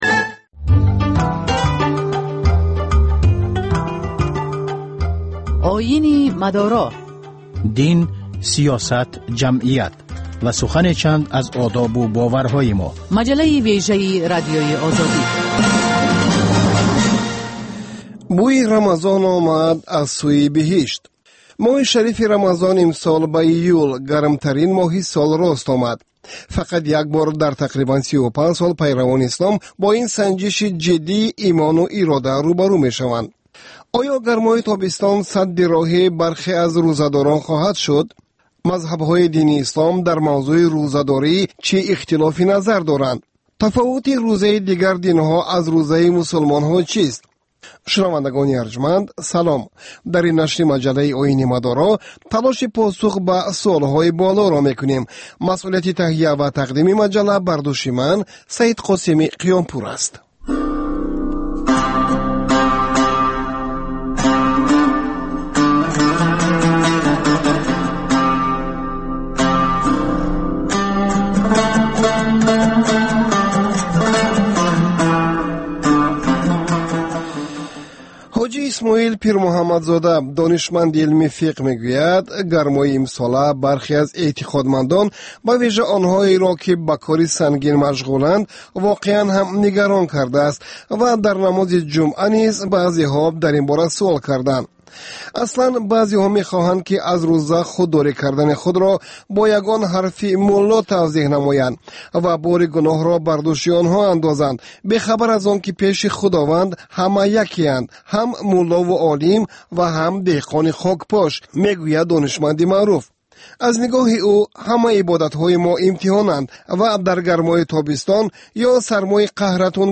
Дин ва ҷомеа. Гузориш, мусоҳиба, сӯҳбатҳои мизи гирд дар бораи муносибати давлат ва дин. Шарҳи фатво ва нукоти мазҳабӣ.